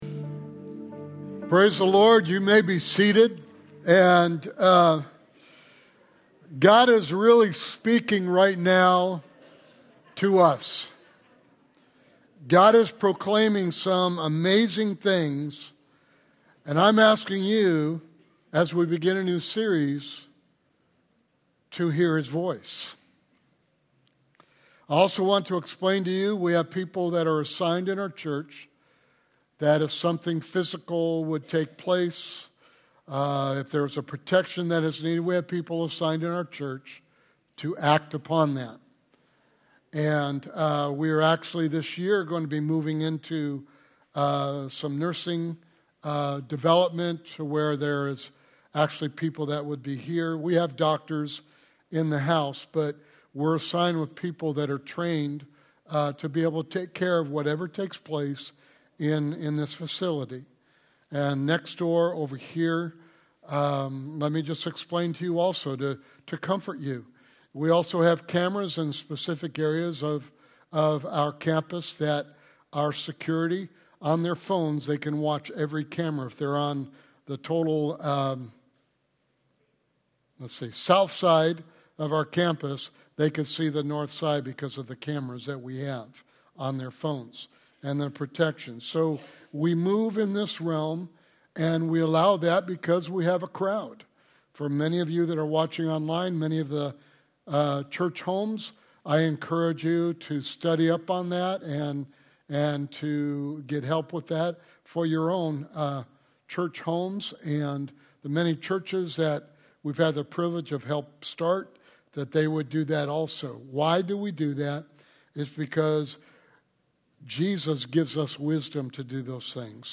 Sermon Series: Tune In and Hear God